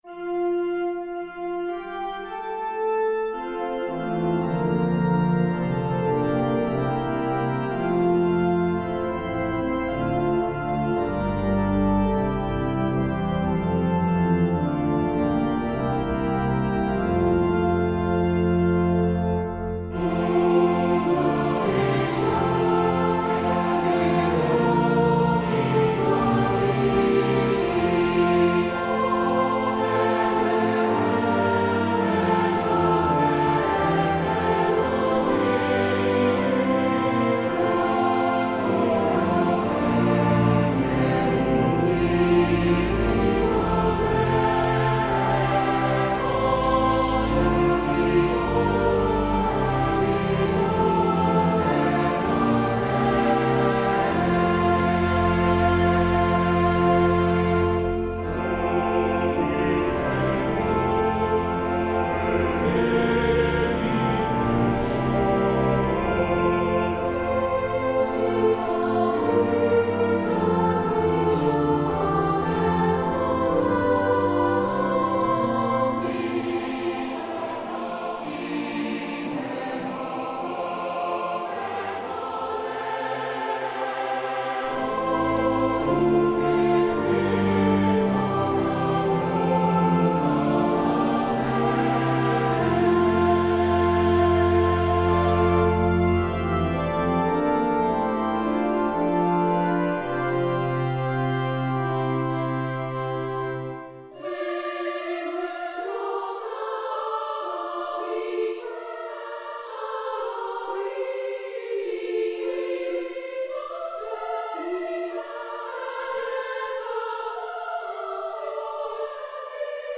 Free descant and arranged verses to the hymn 'O what their joys and their glories must be,' from Peter Abelard's 10th C. Latin original, for which the tune name